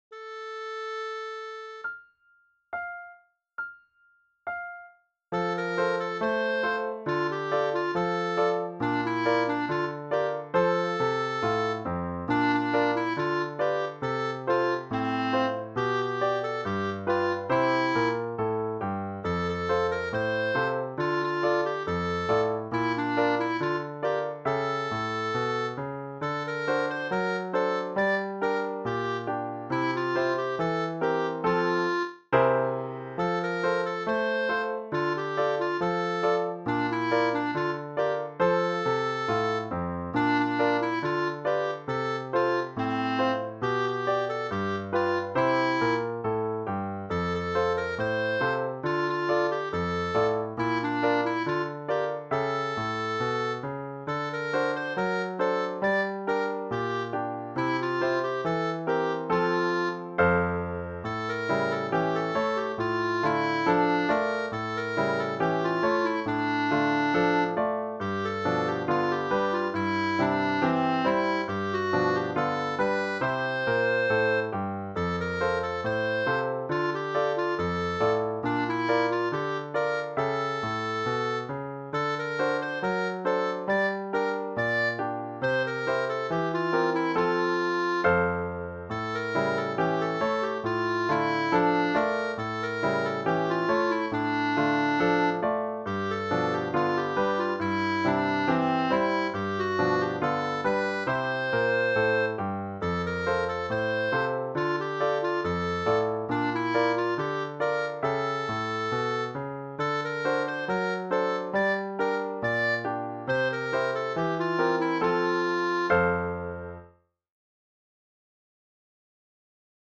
Ici, on pourra acceder à une version accompagnée des mélodies et chansons apprises lors de nos cours.
Une mélodie sympathique pour travailler la petite syncope (tirimri).